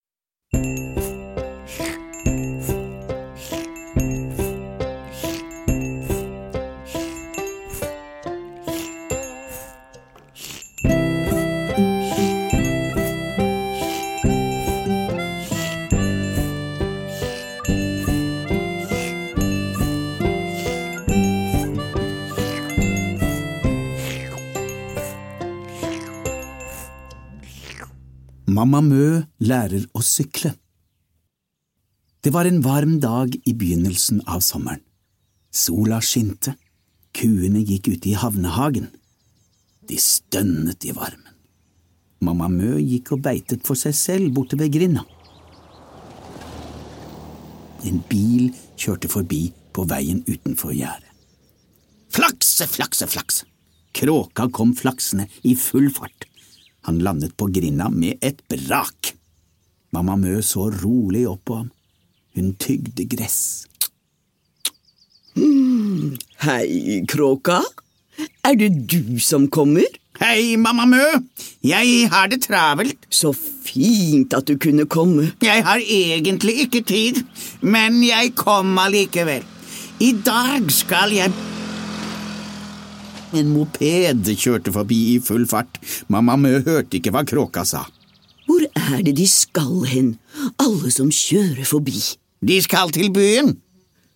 Nedlastbar lydbok
Dette er en dramatisering med skuespillere, musikk og lydeffekter.